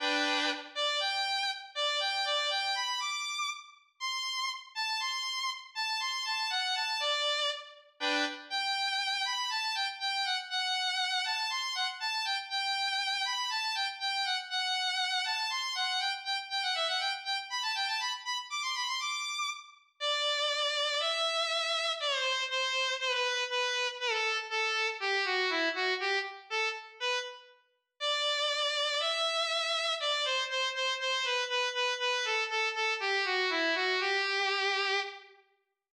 eine-kleine-viol.wav